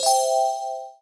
purchase.mp3